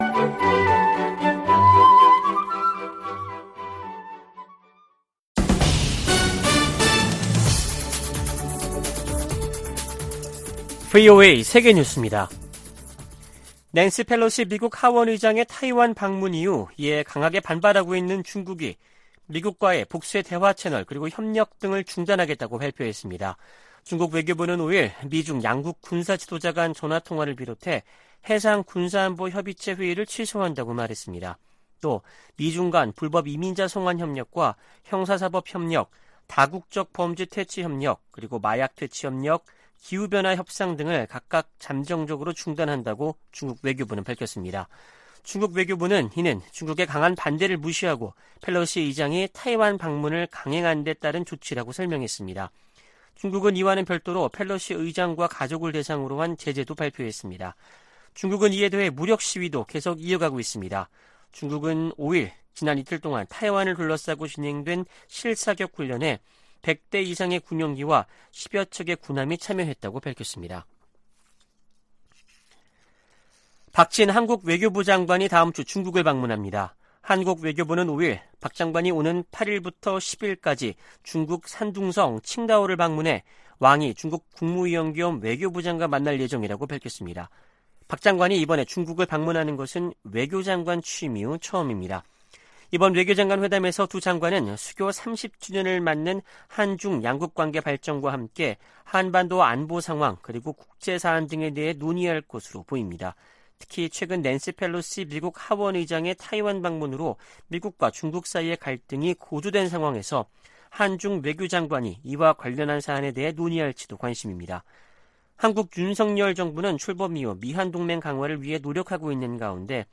VOA 한국어 아침 뉴스 프로그램 '워싱턴 뉴스 광장' 2022년 8월 6일 방송입니다. 토니 블링컨 미 국무장관은 타이완 해협에서 무력으로 변화를 노리는 어떠한 시도에도 반대한다고 말했습니다.